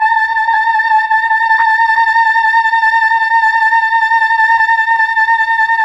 VOX_Chb Fm A_6-L.wav